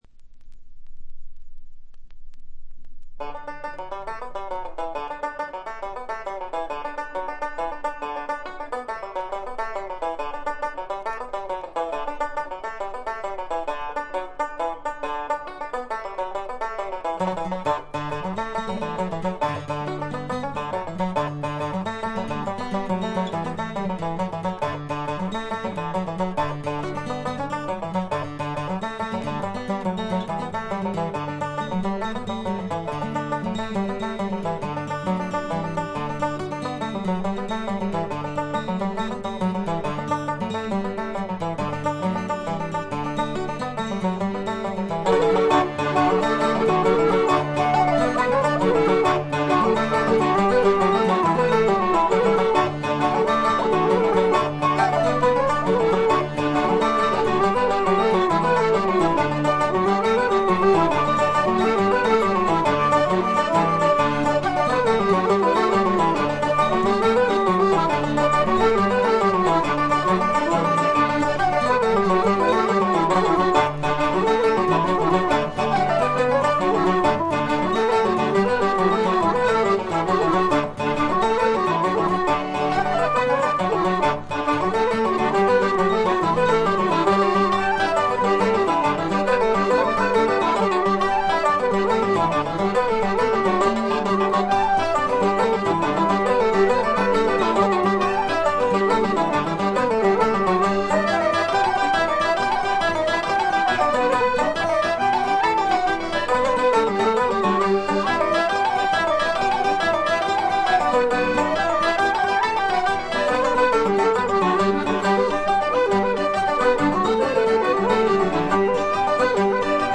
ごくわずかなノイズ感のみ。
アコースティック楽器のみで、純度の高い美しい演奏を聴かせてくれる名作です。
試聴曲は現品からの取り込み音源です。